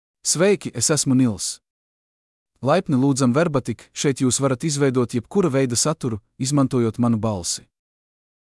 Nils — Male Latvian AI voice
Nils is a male AI voice for Latvian (Latvia).
Voice sample
Listen to Nils's male Latvian voice.
Nils delivers clear pronunciation with authentic Latvia Latvian intonation, making your content sound professionally produced.